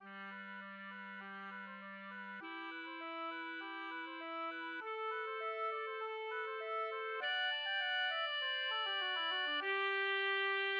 << \new Staff << \relative c'' { \time 4/4 \key g \major \set Staff.midiInstrument="oboe" \tempo 4=100 g8\ppp\< b8 d8 b8 g8 b16( c16) d8 b8 | g8 b16( c16) e8 b8 g8 b16( c16) e8 b8 | a8 b16( c16) e8 d16( c16) a8 b16( c16) e8 c8 | fis8 a16( g16) fis8 e16( d16) c8 a16( g16 fis16 e16 fis16 d16) | g2\fff %{r2 | r1 | r2. d4 | %} } >> \new Staff << \relative c' { \time 4/4 \key g \major \set Staff.midiInstrument="clarinet" g1\ppp\< | e'1 | a1 | d1 | g,2\fff %{ r2 | r1 | r2. d4 |%} } >> >>